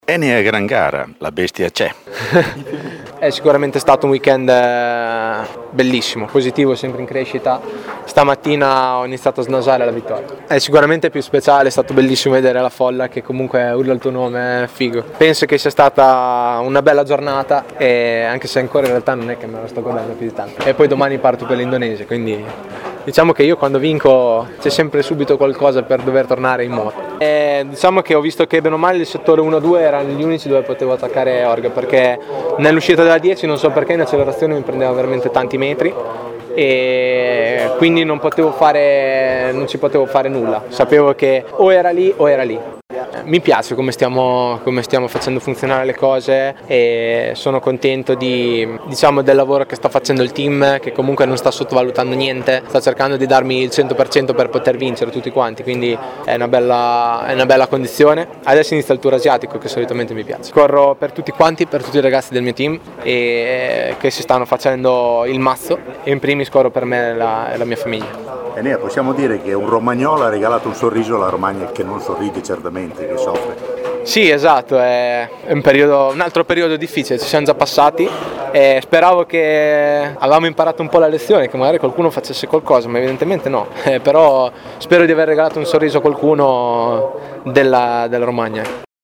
E il sorpasso di Bastianini alla Rio, stile Marquez, è un sorpassone al limite, ma regolarissimo e lo spiega bene nell’intervista.